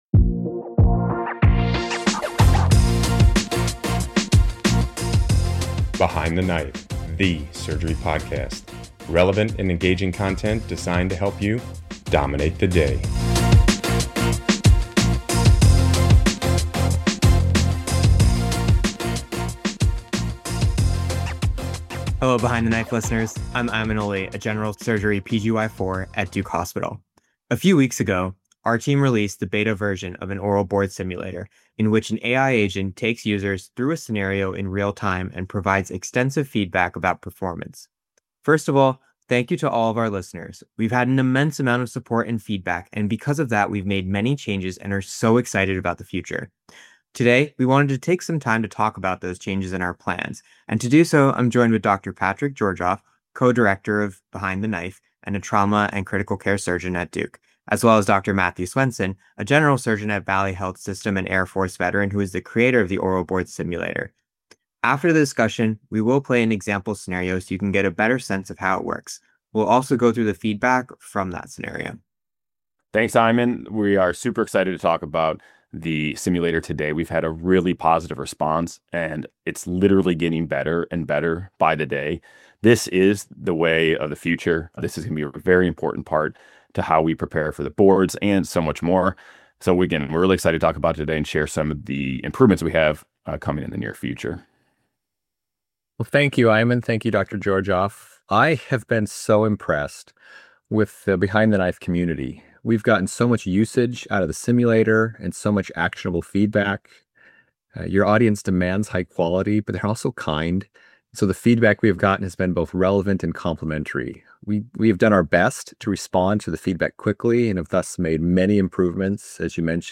We've also included a full example test for you to listen to.